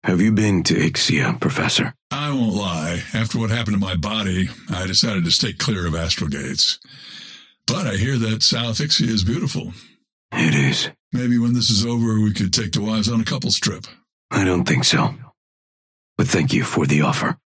Dynamo and Grey Talon conversation 3